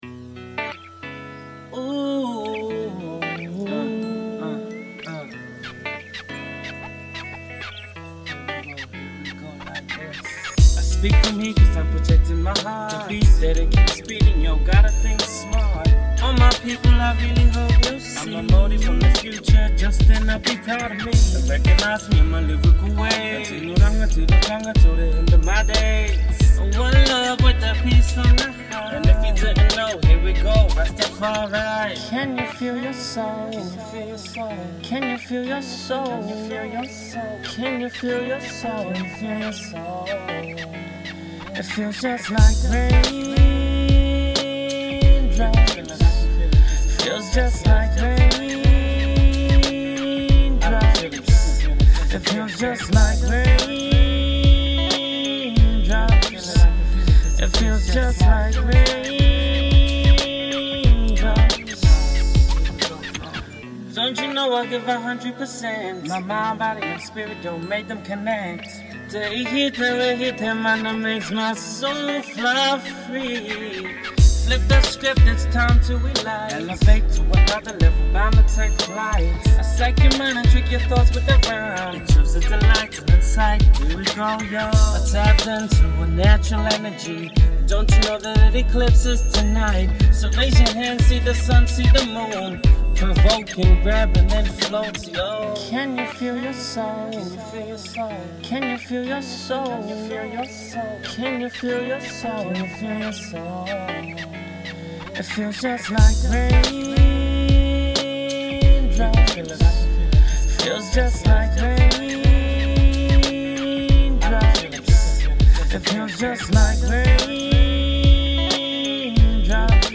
Before, Demo Mix: